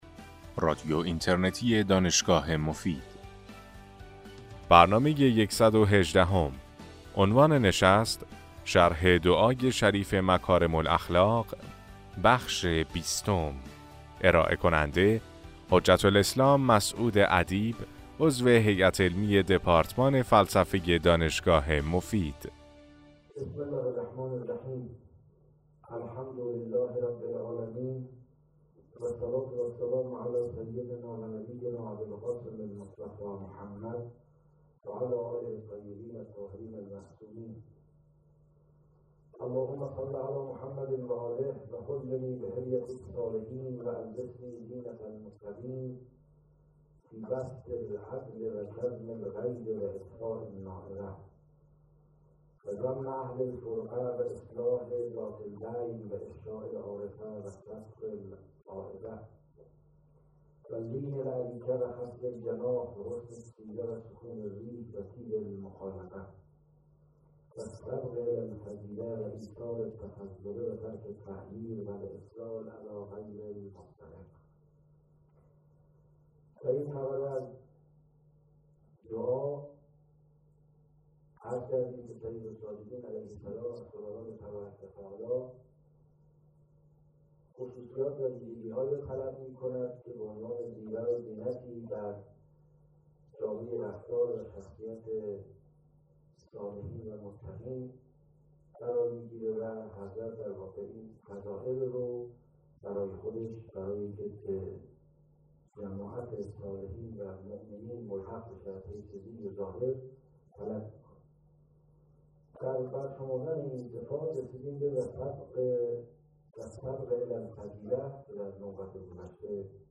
سلسله سخنرانی